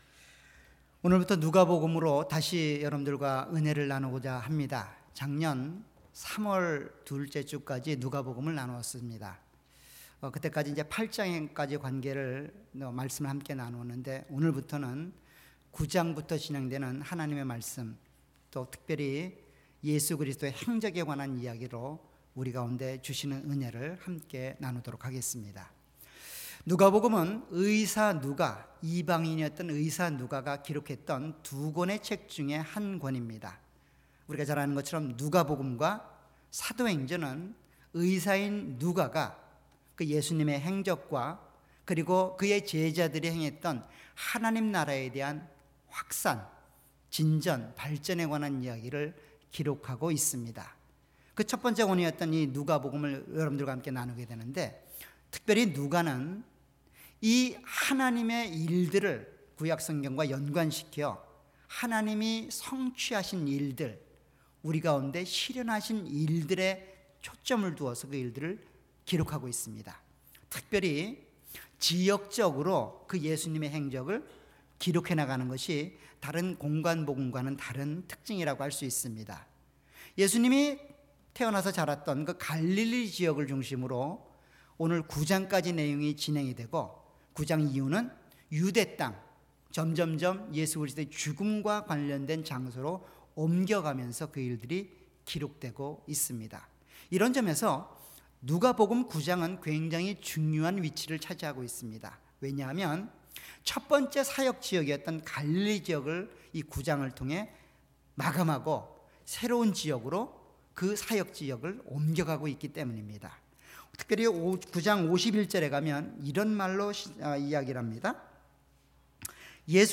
All Sermons
Series: 주일예배.Sunday